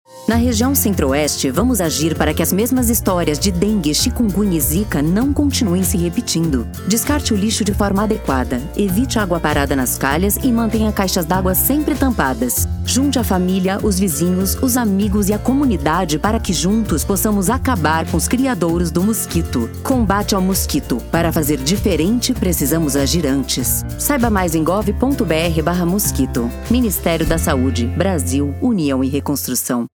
Áudio - Spot 30seg - Campanha de Combate ao Mosquito Centro-Oeste - 1,1mb .mp3